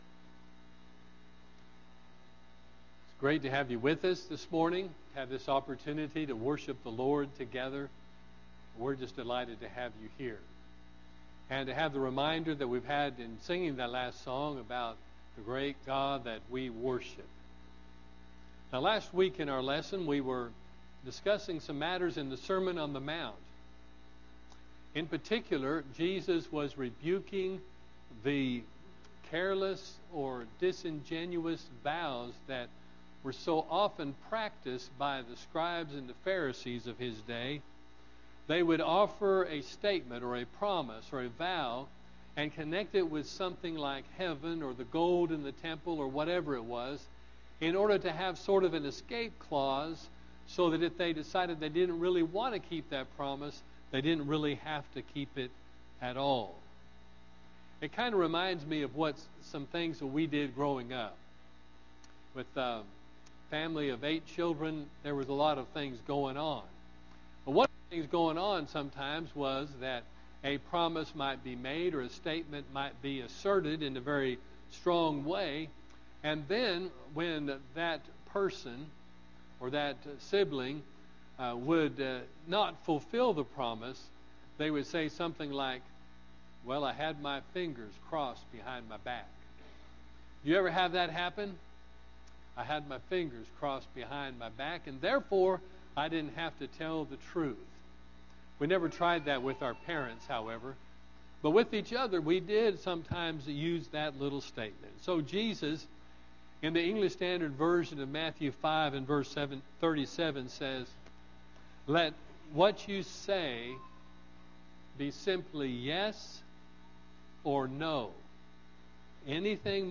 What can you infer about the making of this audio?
AM Sermon – Greenbrier church of Christ